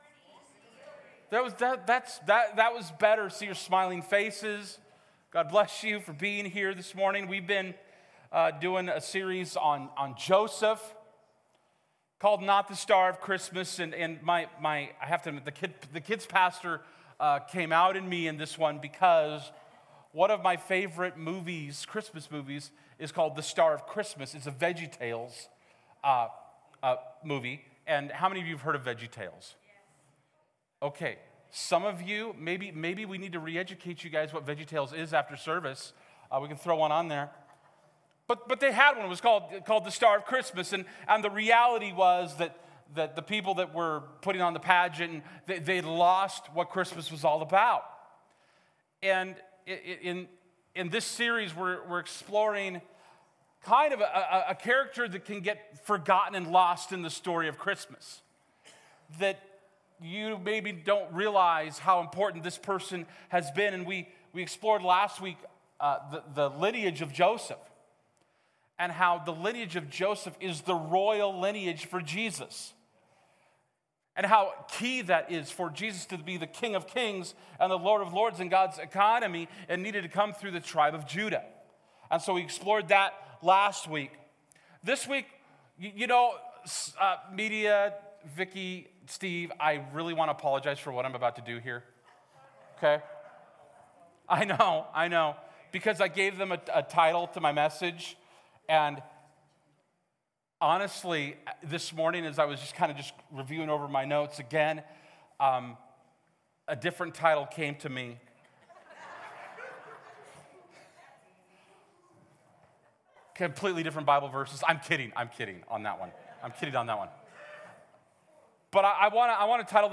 Genesis 38:24-26 Service Type: Sunday Morning « Not the Star of Christmas Like Father